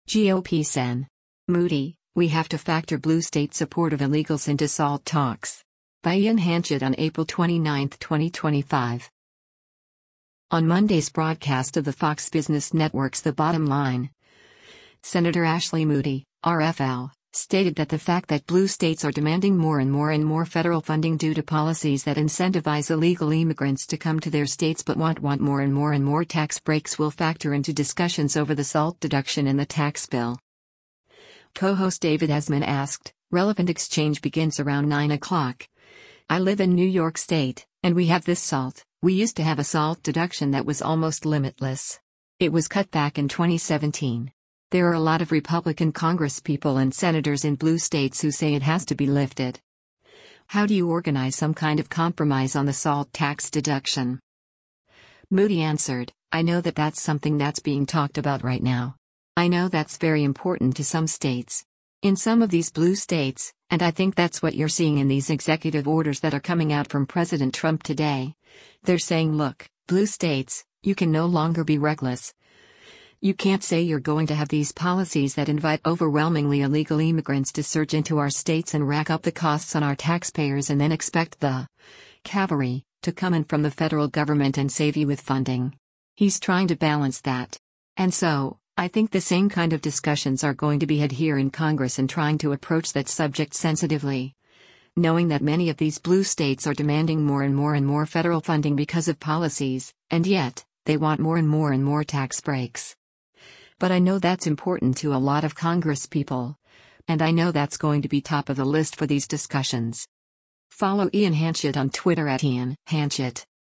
On Monday’s broadcast of the Fox Business Network’s “The Bottom Line,” Sen. Ashley Moody (R-FL) stated that the fact that blue states are “demanding more and more and more federal funding” due to policies that incentivize illegal immigrants to come to their states but want “want more and more and more tax breaks” will factor into discussions over the SALT deduction in the tax bill.